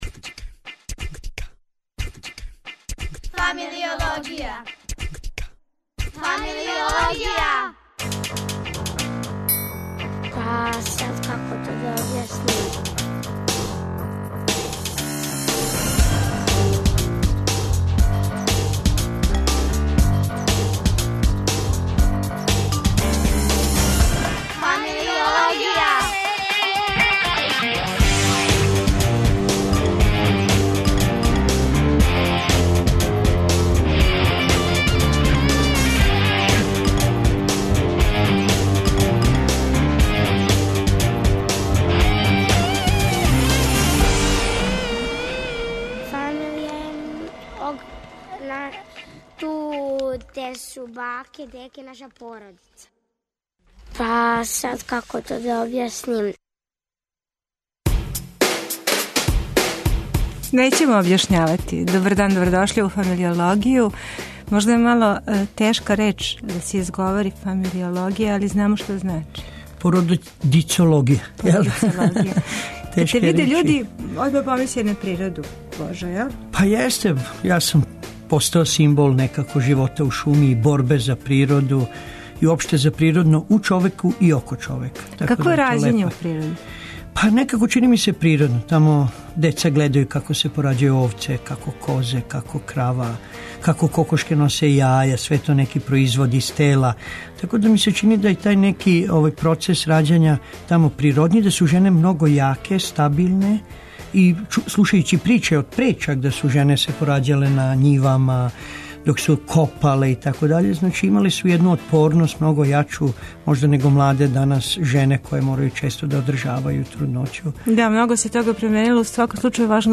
И још - разговор о одузимању деце (несавесним) родитељима... Уз Азбуку звука и мноштво тонских изненађења, пролази нам већ друга емисија...